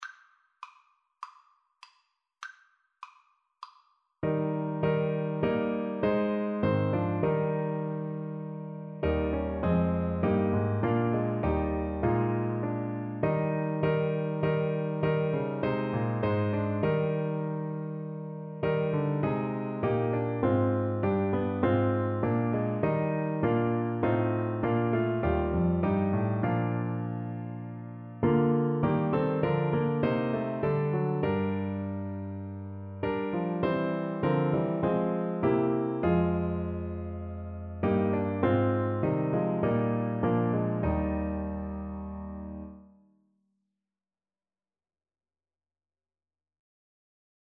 Trumpet version
4/4 (View more 4/4 Music)
D5-C6
Classical (View more Classical Trumpet Music)